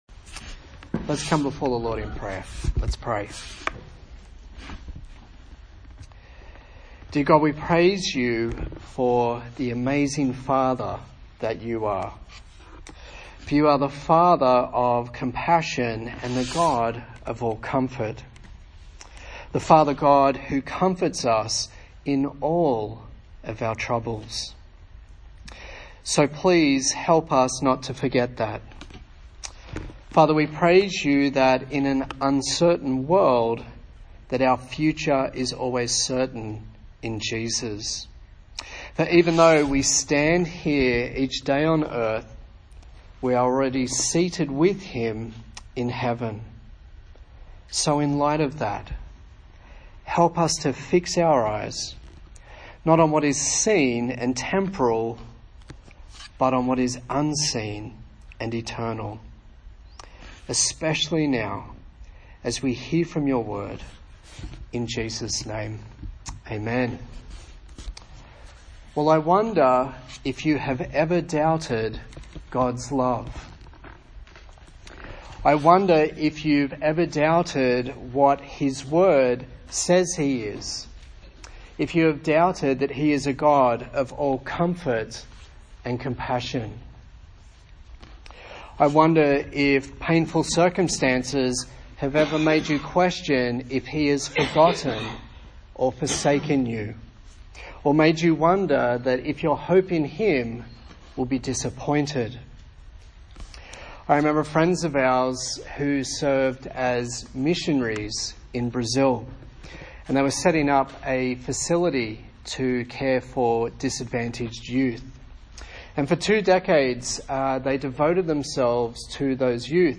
Isaiah Passage: Isaiah 49:8-26 Service Type: TPC@5